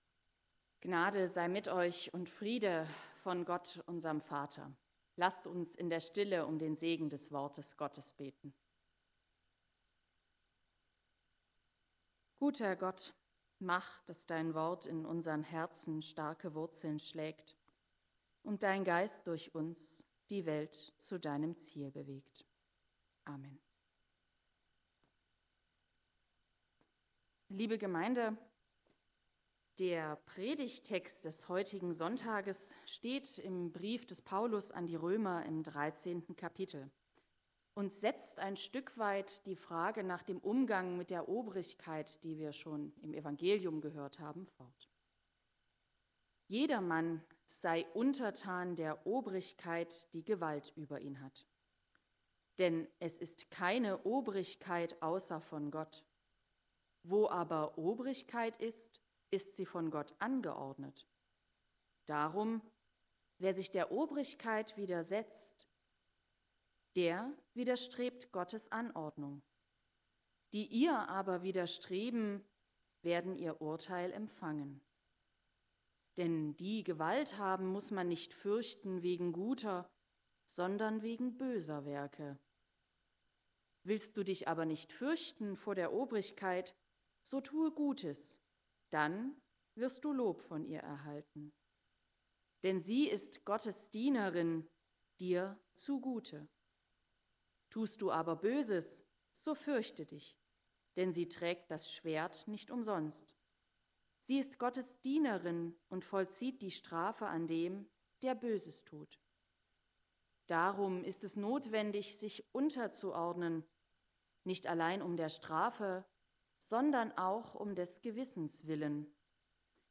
1-7 Gottesdienstart: Predigtgottesdienst Obercrinitz Paulus schreibt in seinem Brief an die Römer von Gerhorsam gegenüber der Obrigkeit.